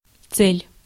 Ääntäminen
US US : IPA : /ˈtɑrɡɪt/ IPA : [ˈtʰɑ˞ɡɪt] UK : IPA : /tɑːɡɪt/